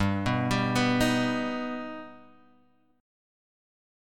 G+ chord